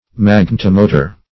Magnetomotor \Mag`net*o*mo"tor\, n.